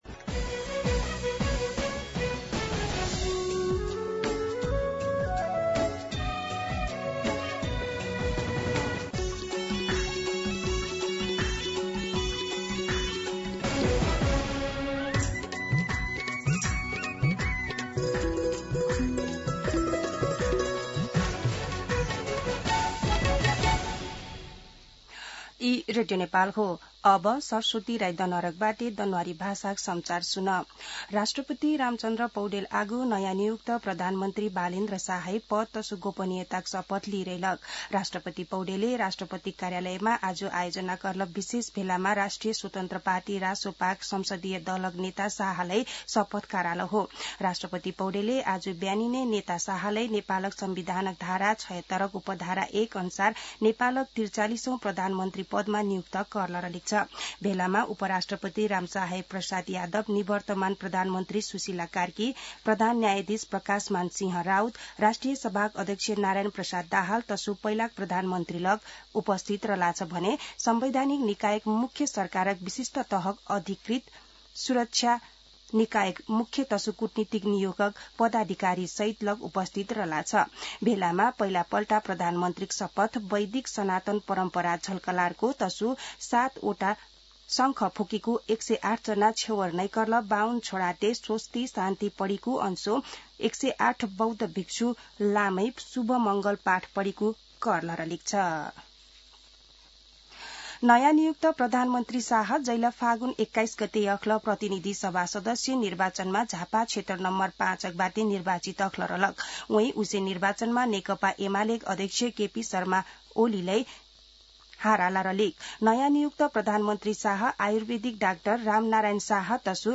दनुवार भाषामा समाचार : १३ चैत , २०८२